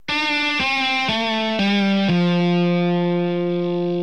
Smear Bends
A smear bend (aka small bend) is produced when the string is bent very slightly (approximately a quarter note). This particular bend is very important in blues music as it creates a slightly “off” sounding note and gives it an authentic blues flavor.
smearbend on guitar string
smearbend.mp3